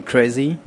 描述：我们在巴塞罗那Musichackday期间记录了一些黑客。我们要求他们给我们一个关于他们工作的书呆子描述。然后我们把一些话剪下来，用在我们的装置作品"音乐青蛙鸭"中。
标签： 巴塞罗那 令人讨厌 musichackday 书呆子 演讲 讲词
声道立体声